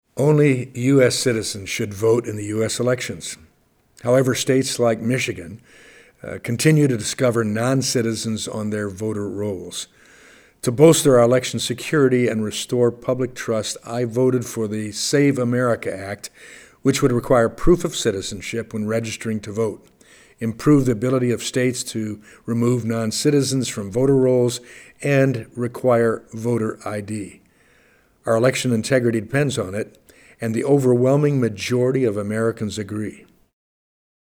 Here is Tim Walberg with more: